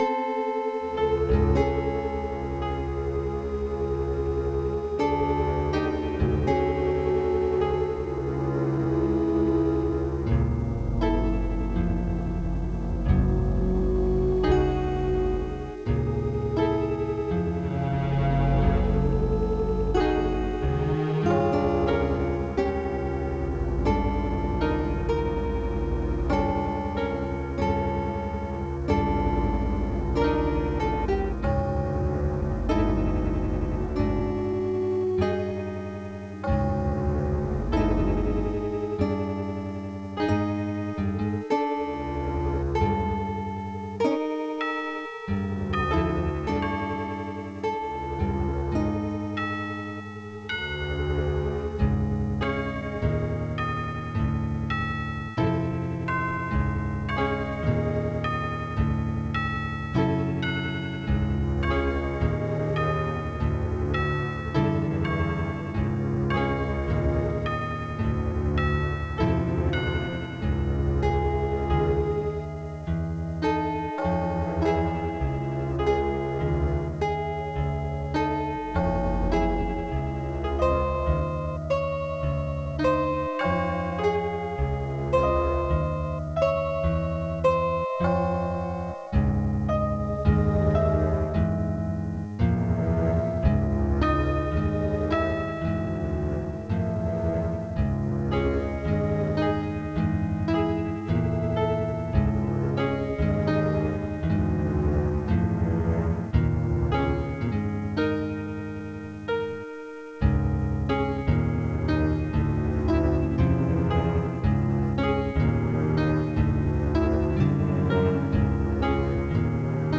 spooky
strange
Warped
Spooky style tune full of twists